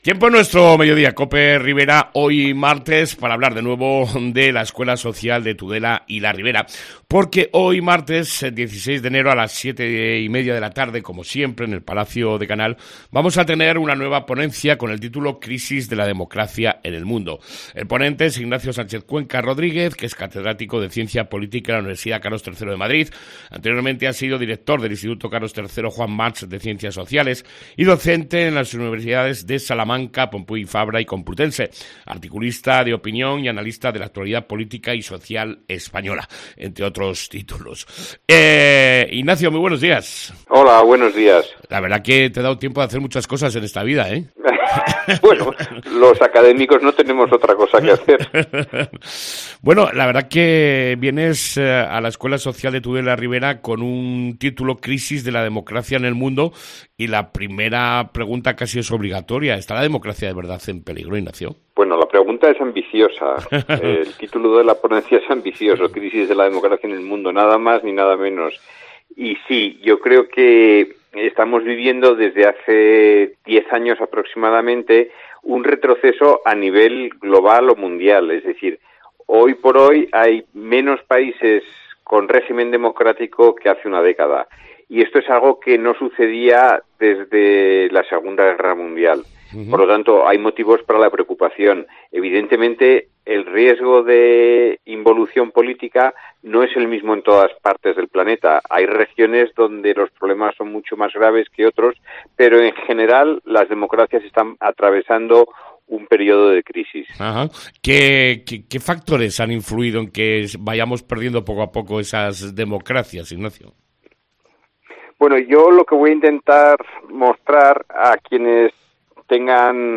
NUEVA PONENCIA DE LA ESCUELA SOCIAL DE TUDELA Y LA RIBERA